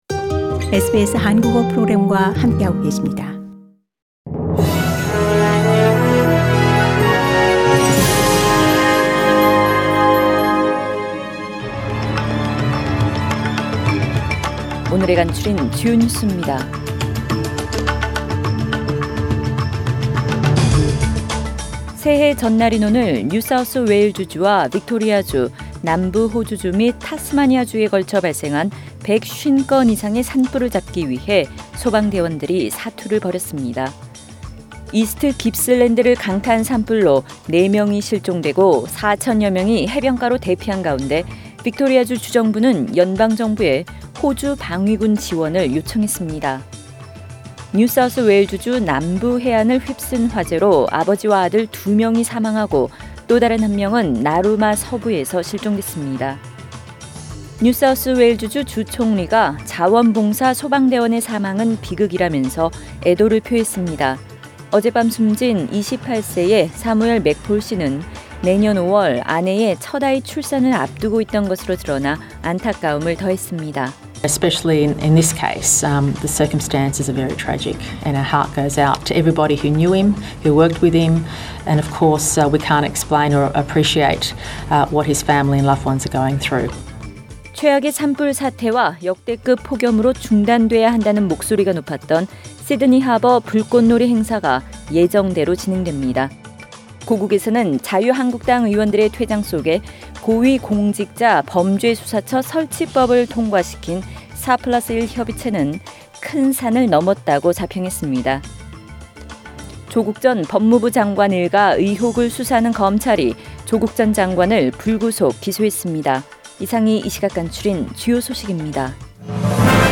Major stories from SBS Korean News on Tuesday 31 December